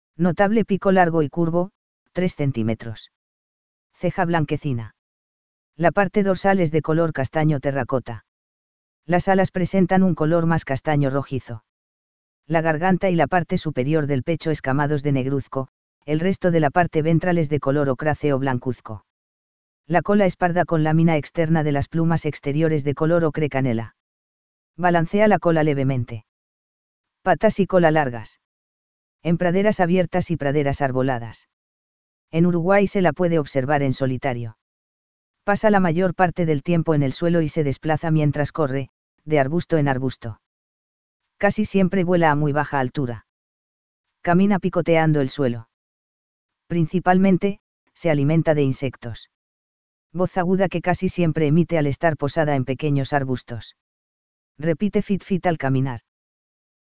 Upucerthia dumetaria - Bandurrita
Voz aguda que casi siempre emite al estar posada en pequeños arbustos.
Repite fit fit al caminar.
Bandurrita.mp3